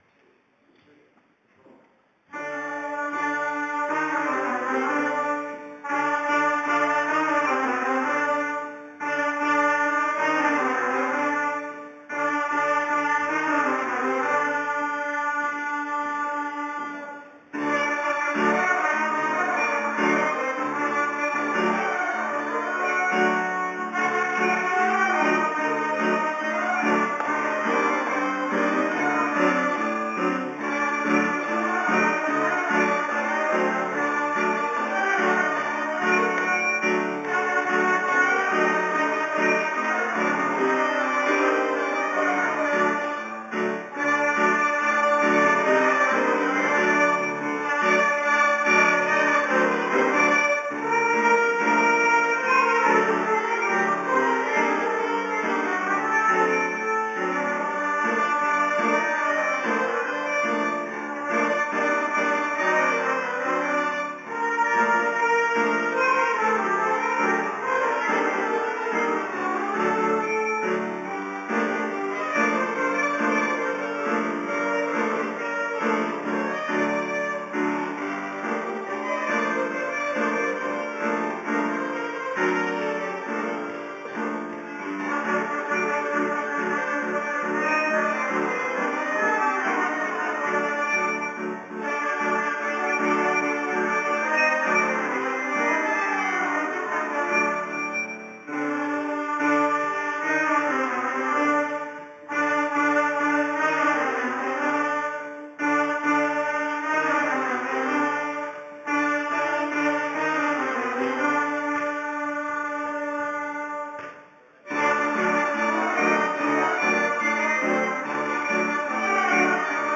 Concert at Hebrew College
It’s a big beautiful room but has funny acoustics; the front half has a very high ceiling and the back half has a normal-height ceiling, and the sound sometimes doesn’t make it very far past the dividing point.
It was a bigger group, maybe 18 kids, including two trumpets, at least four clarinets, and two flutes. I guess a few violins too, a piano, and the drummers were absent.